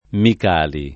Micali [ mik # li ]